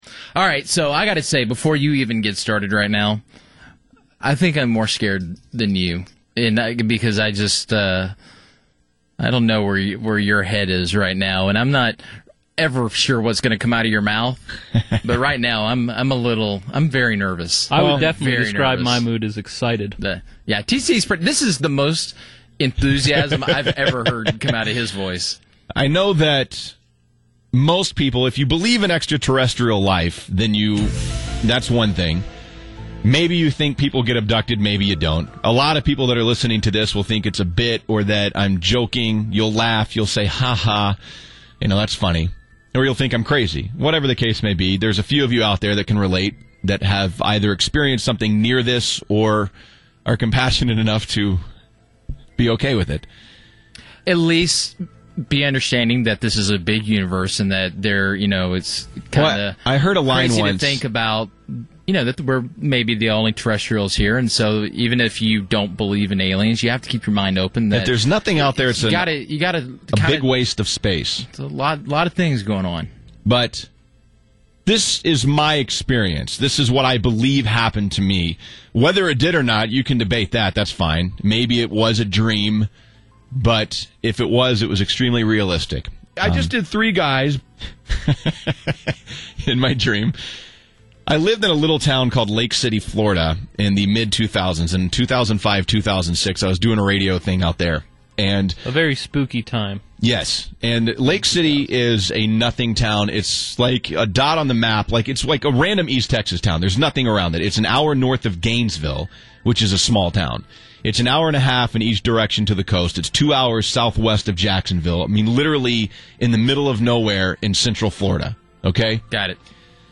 The entire saga is spliced together below for your extra-terrestrial audio edification. https